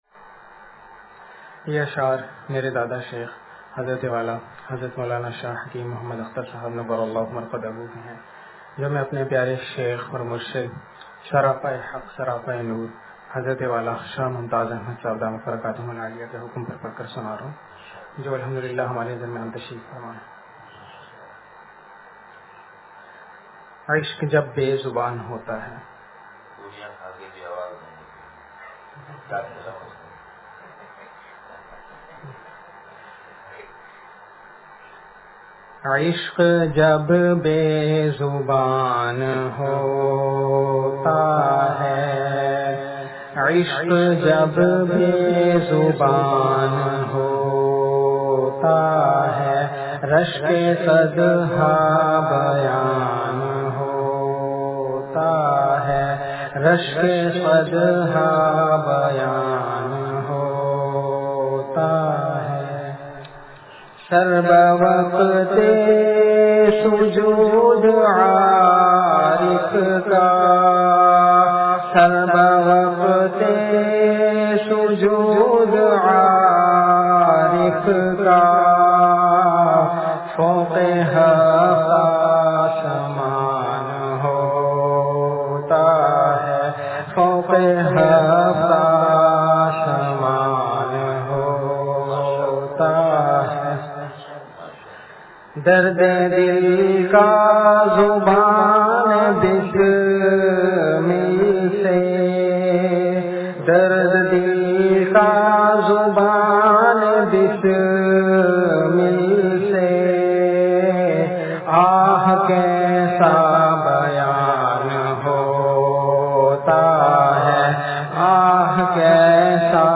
عشق جب بے زبان ہوتا ہے – بیان – اتوار